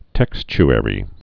(tĕksch-ĕrē)